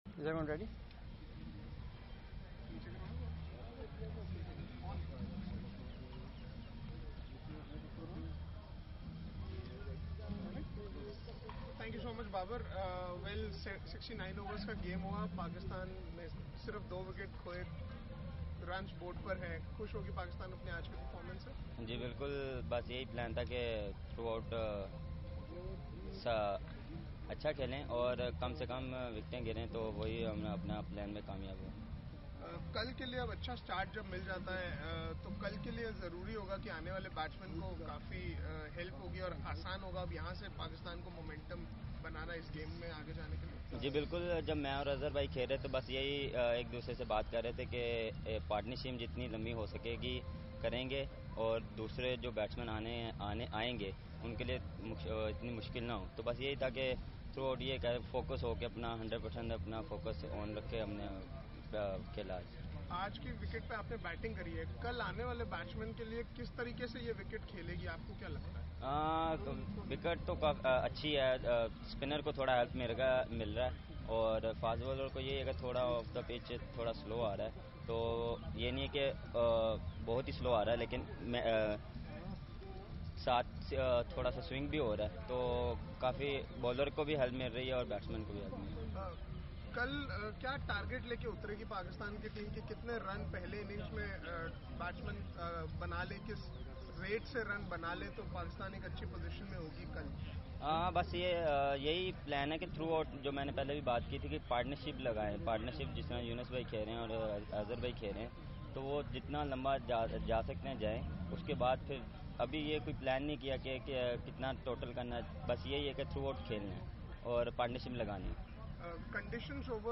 Babar Azam press conference after day one of third test match in Roseau, Dominica